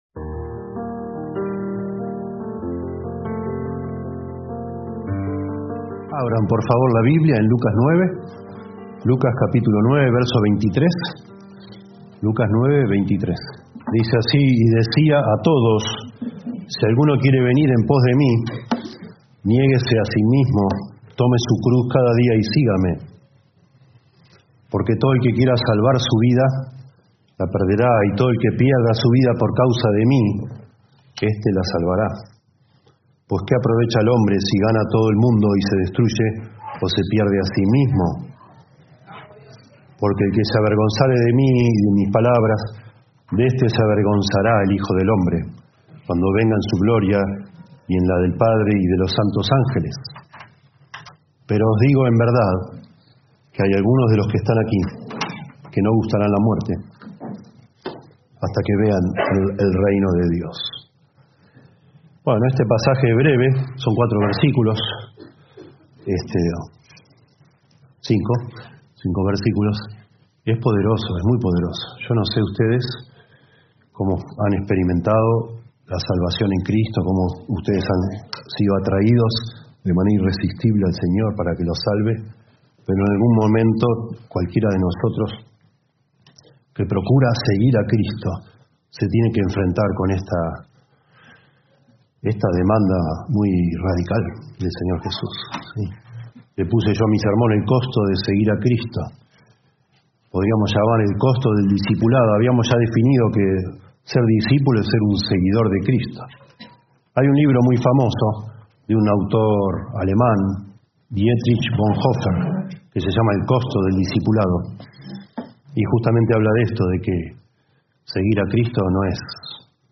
Reina-Valera 1960 (RVR1960) Video del Sermón Audio del Sermón Descargar audio Temas: